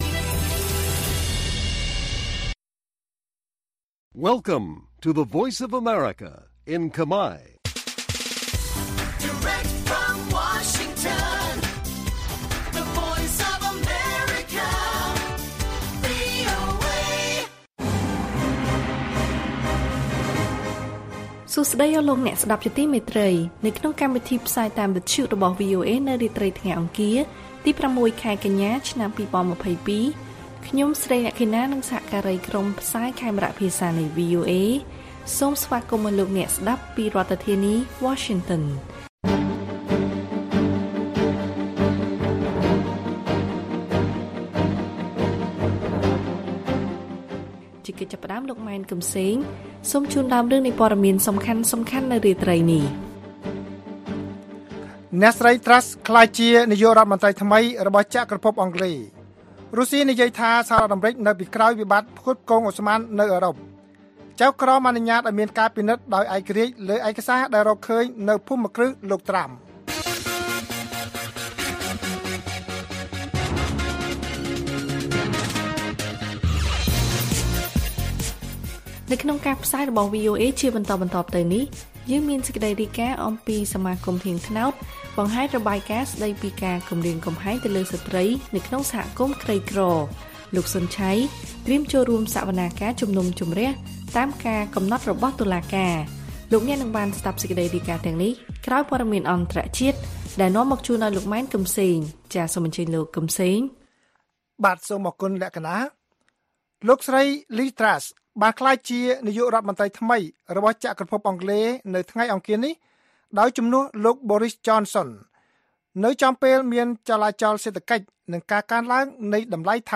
ព័ត៌មានពេលរាត្រី ៦ កញ្ញា៖ អ្នកស្រី Truss ក្លាយជានាយករដ្ឋមន្ត្រីថ្មីរបស់ចក្រភពអង់គ្លេស